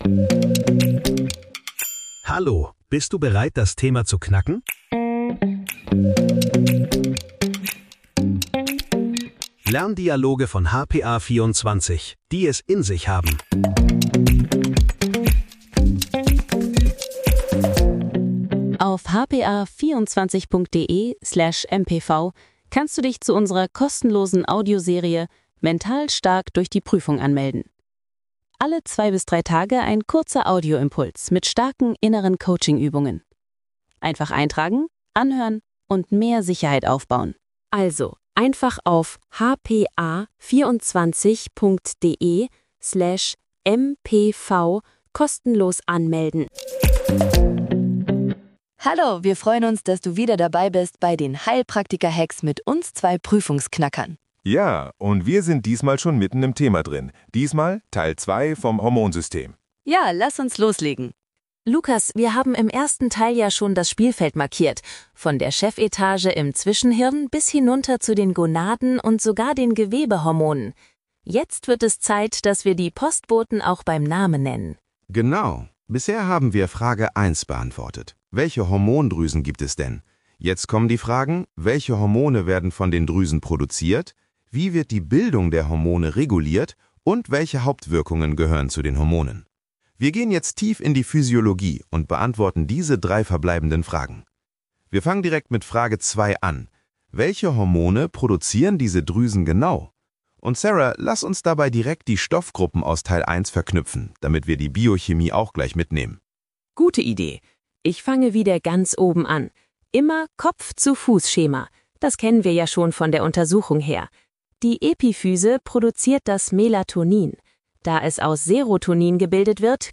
Lerndialoge für deinen Prüfungserfolg